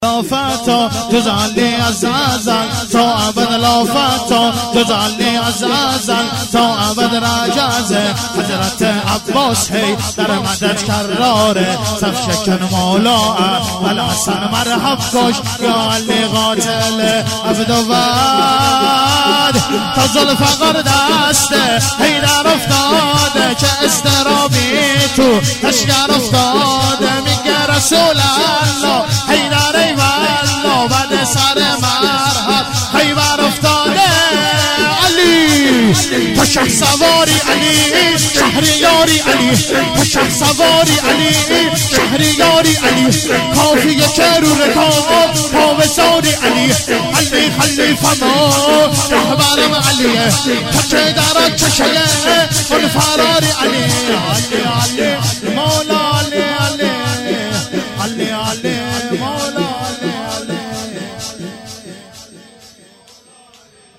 شهادت جواد الایمه(ع) جمعه 19مرداد